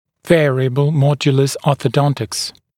[‘veərɪəbl ‘mɔdjələs ˌɔːθə’dɔntɪks][‘вэариэбл ‘модйэлэс ˌо:сэ’донтикс]ортодонтия вариативных модулей, т.е. ортодонтическое лечение с применением дуг из различных материалов, имеющих разные модули упругости